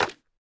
resin_brick_place4.ogg